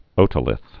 (ōtə-lĭth)